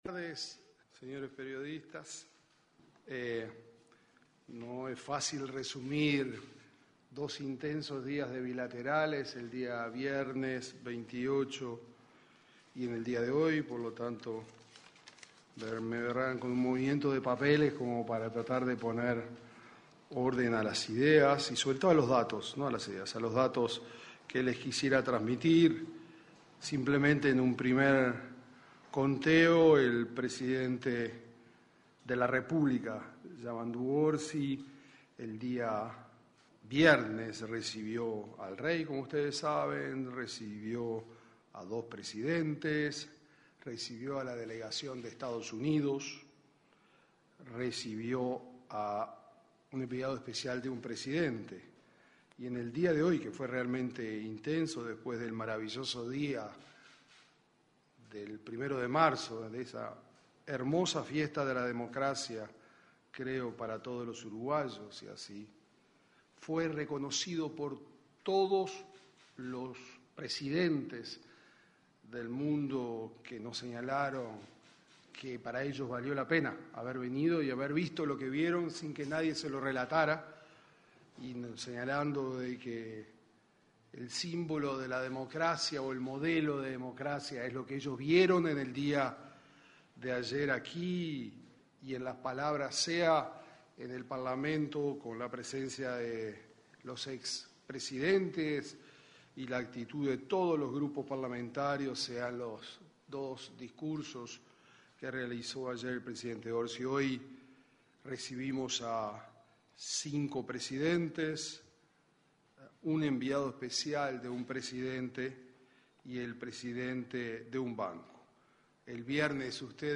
Conferencia de prensa de ministro de Relaciones Exteriores, Mario Lubetkin
Este domingo 2 de marzo, el ministro de Relaciones Exteriores, Mario Lubetkin, se expresó en una conferencia de prensa en el edificio José Artigas.